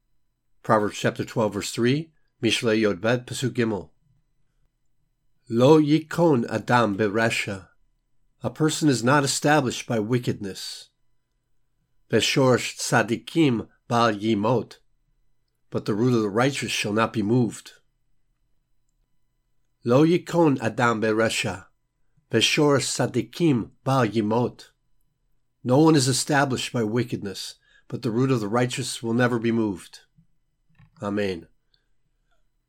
Hebrew Lesson
Proverbs 12:3 reading (click for audio):